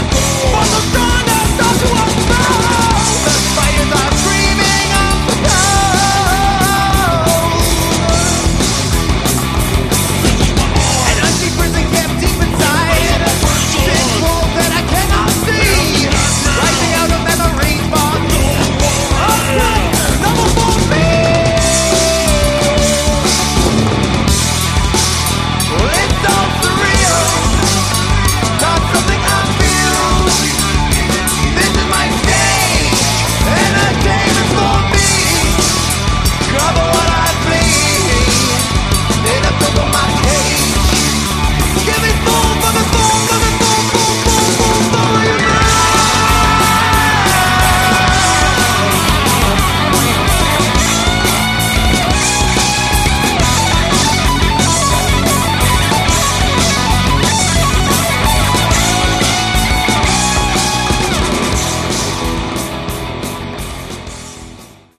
Category: Melodic Metal
guitars, keyboards, backing vocals
drums, backing vocals
bass, backing vocals
lead vocals